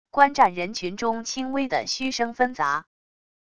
观战人群中轻微的嘘声纷杂wav音频